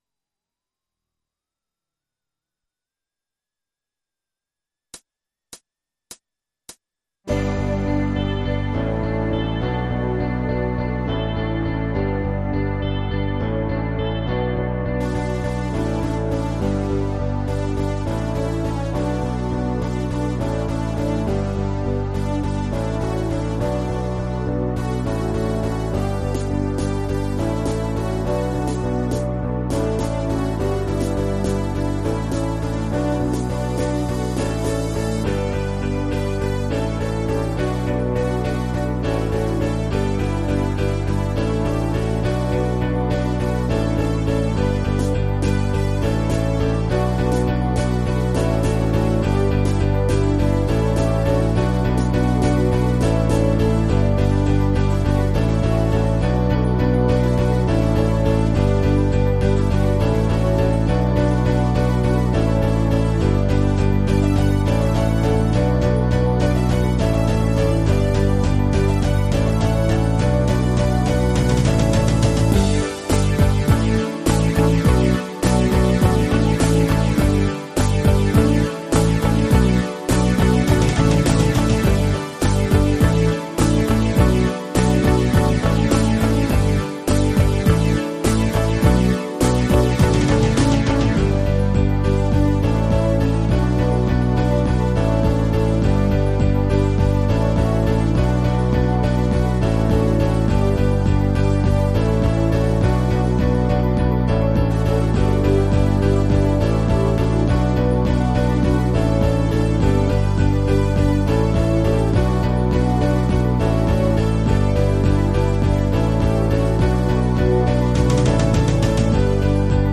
PRO MIDI Karaoke INSTRUMENTAL VERSION